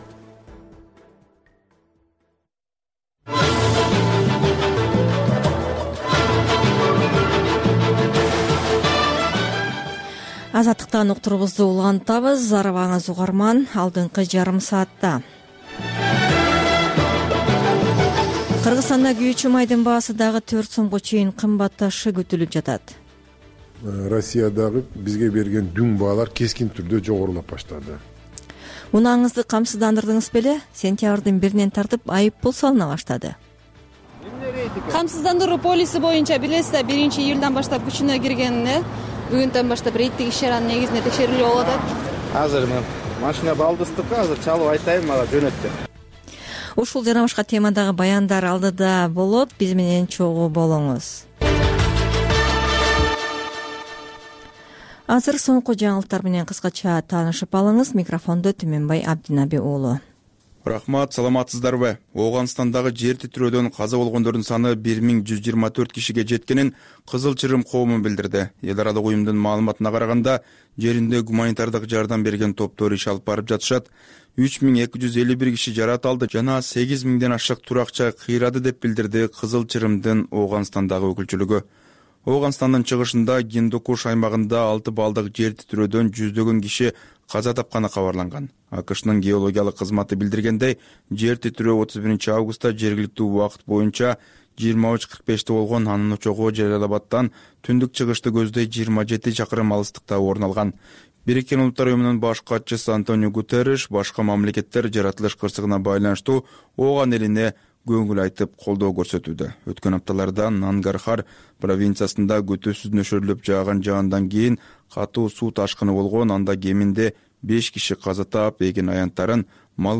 Бул үналгы берүү ар күнү Бишкек убакыты боюнча саат 18:30ден 19:00га чейин обого түз чыгат.